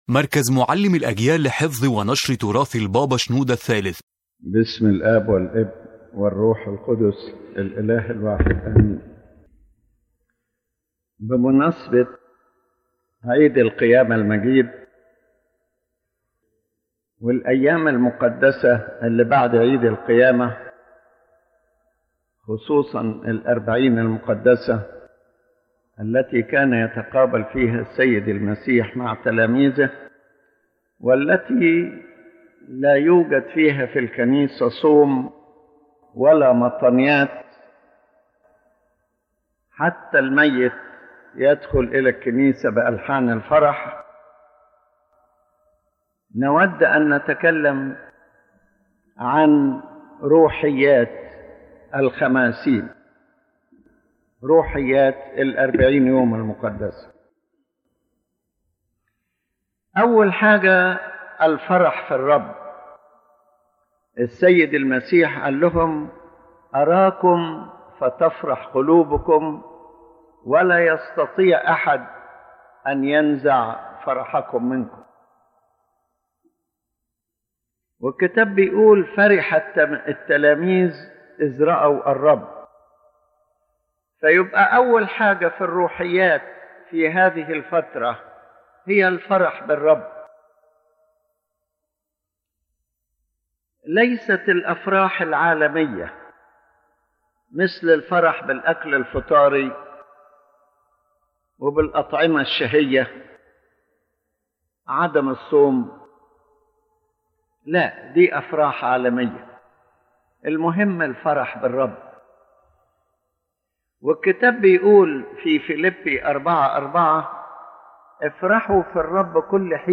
The lecture speaks about the spiritual life that the believer should live during the Holy Fifty Days, which are the days following the Glorious Feast of the Resurrection. This period is a time of deep spiritual joy because of the Resurrection of Christ, but it is not merely external joy; rather it is a call to enter a deeper spiritual life based on rejoicing in God, living in the matters of the Kingdom of God, and preparing for spiritual service.